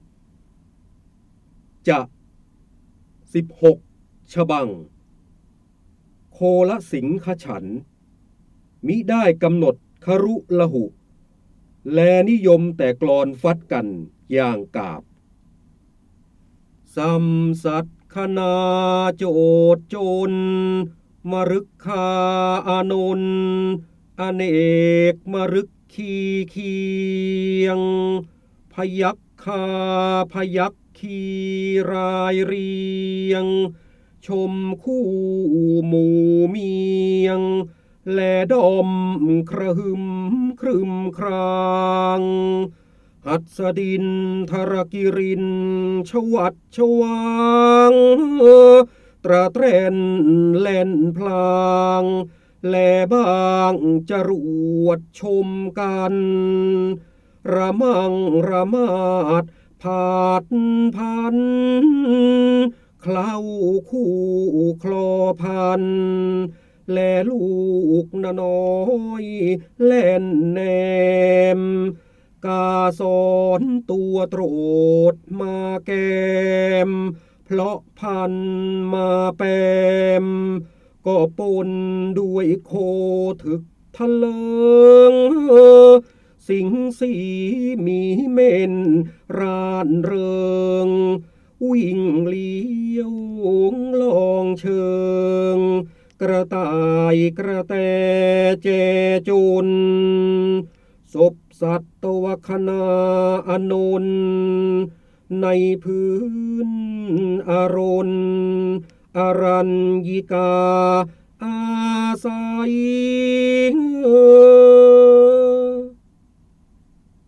เสียงบรรยายจากหนังสือ จินดามณี (พระโหราธิบดี) จ o o o o o o o ฯ ๑๖ ฉบัง
คำสำคัญ : ร้อยแก้ว, การอ่านออกเสียง, ร้อยกรอง, พระเจ้าบรมโกศ, จินดามณี, พระโหราธิบดี